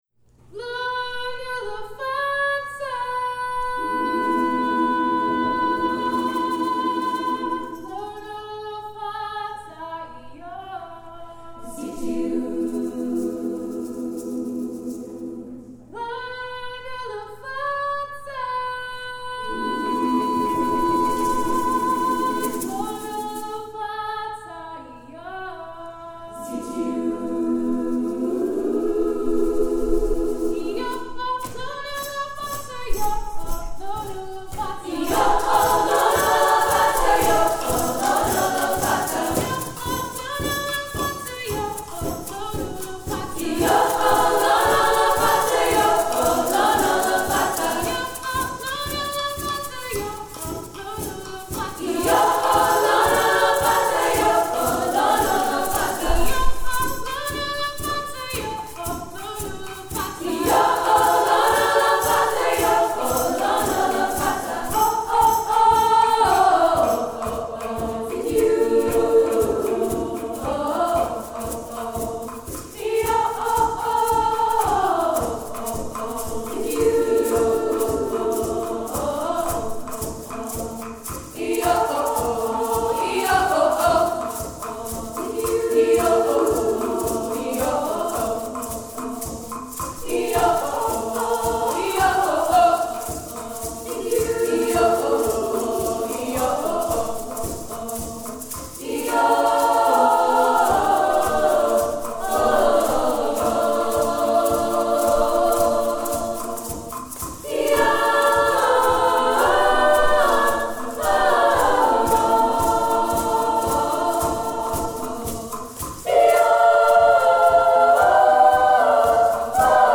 Composer: South African Greeti
Voicing: SSAA and Percussion